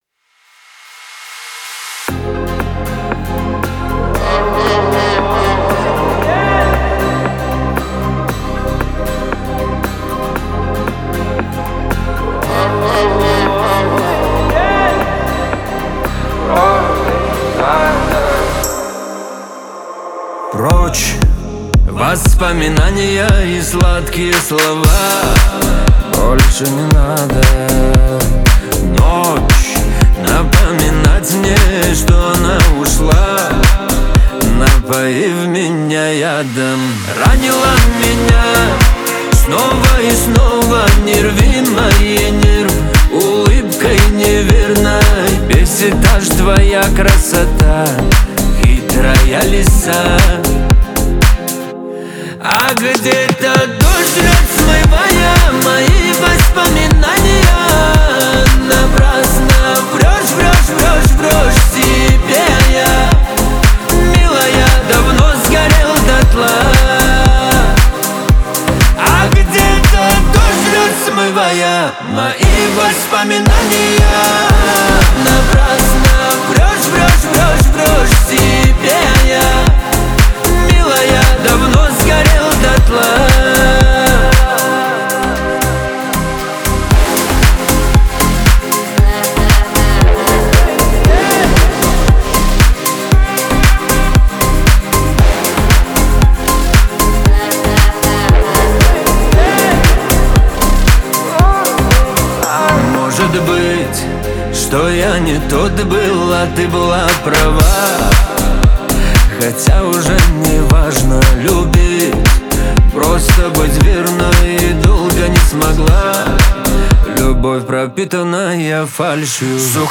Шансон песни
шансон музыка